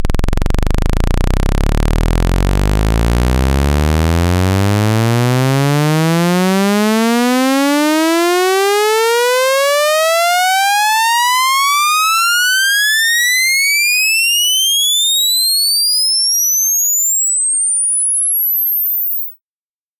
To understand the spectrograms, time is left to right—a 20 second sweep from 20 Hz to 20 kHz of a sawtooth.
But if the idea of aliasing bothers you, and you want at least 18 kHz coverage, 34 wave tables will get you this, at 44.1 kHz sample rate: